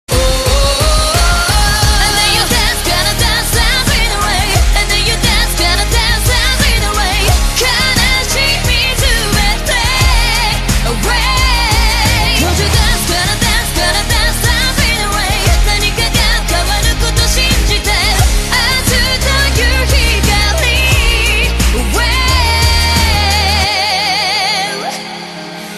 M4R铃声, MP3铃声, 日韩歌曲 76 首发日期：2018-05-15 14:53 星期二